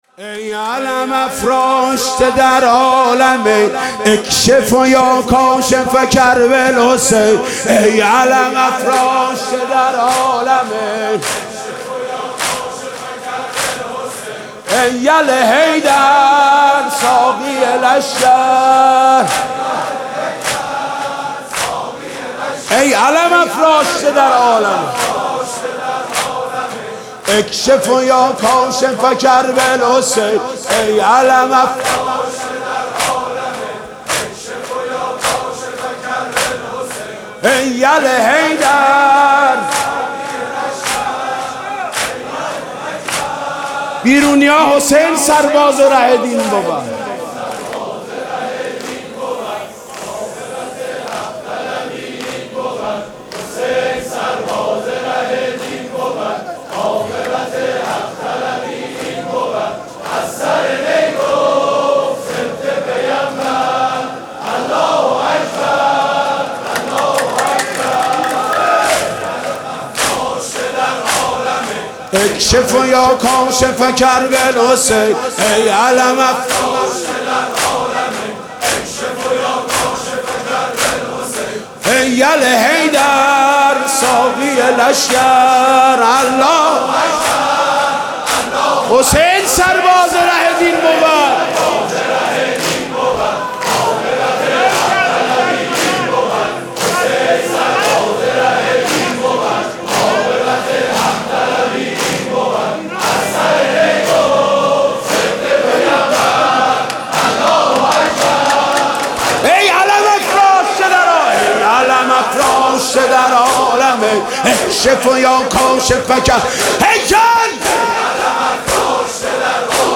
«محرم 1396» (شب تاسوعا) دودمه: ای علم افراشته در عالمین
«محرم 1396» (شب تاسوعا) دودمه: ای علم افراشته در عالمین خطیب: حاج محمود کریمی مدت زمان: 00:05:49